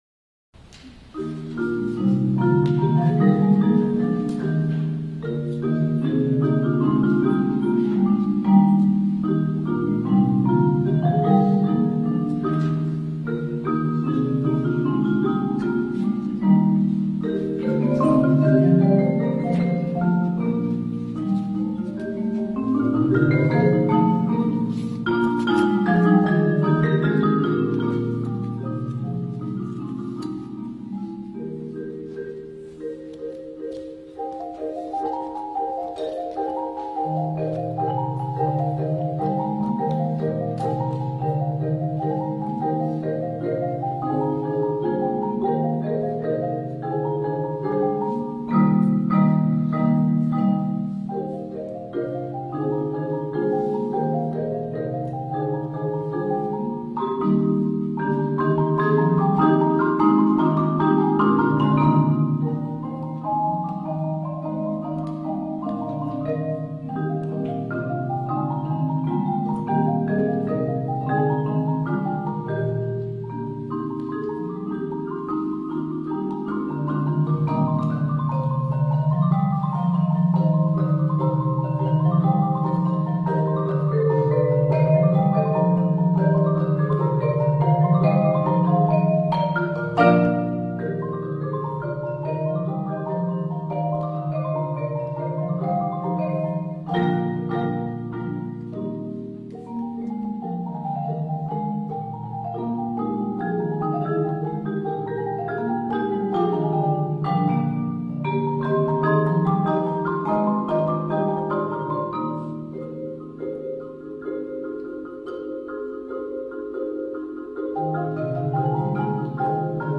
Genre: Marimba Quartet
Marimba 1 (4-octave)
Marimba 2 (4-octave)
Marimba 3 (4.3-octave)
Marimba 4 (5-octave with an alternate 4.3-octave part)